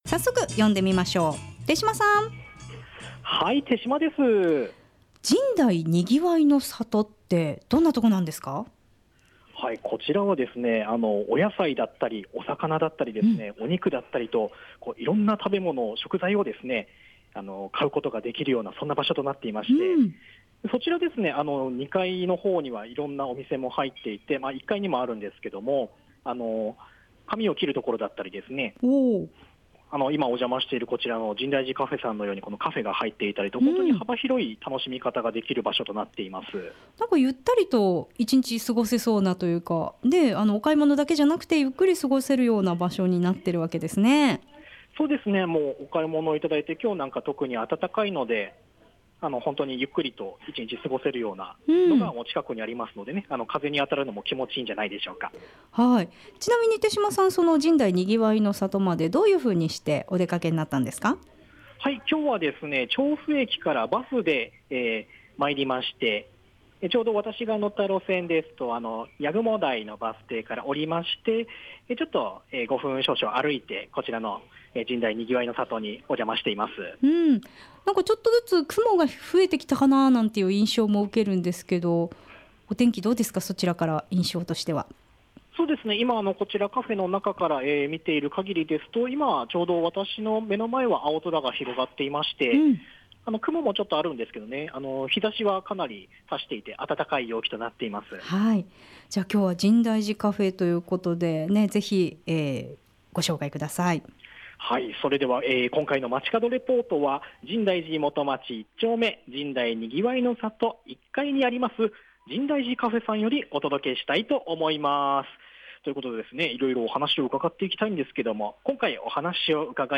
さて今回の街角レポートは、深大寺元町１丁目「深大にぎわいの里」の１階にあります、
放送中にもたくさんのお客様がのんびりと過ごされていました。 店内にはコーヒーの香りが満ちていて、落ち着いた音楽が流れています。